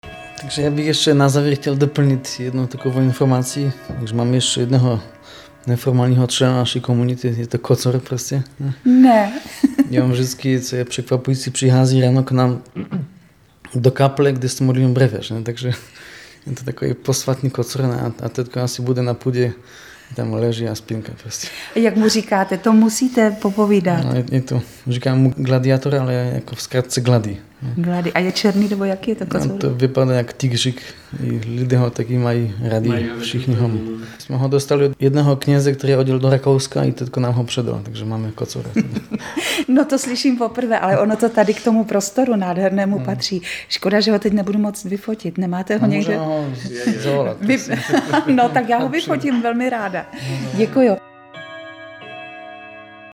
Rozloučit se nakonec přišel i kocour Glady (audio MP3)kocour Glady.